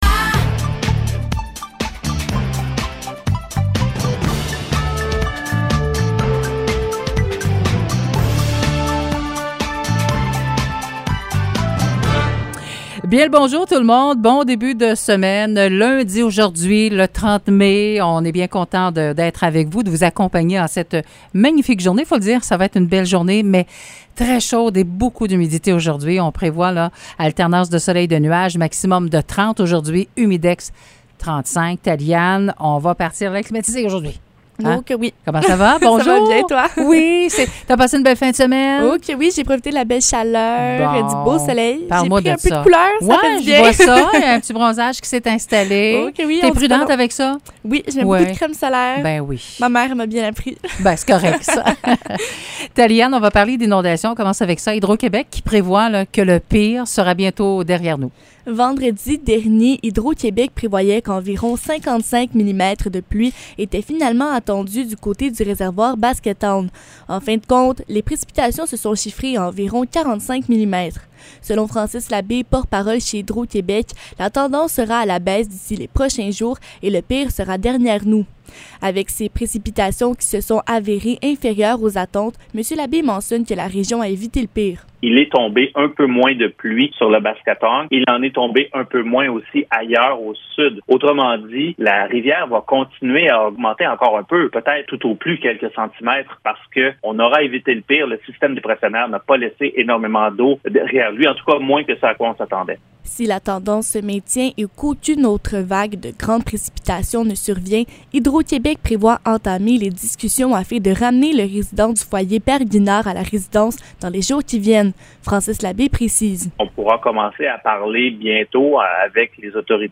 Nouvelles locales - 30 mai 2022 - 9 h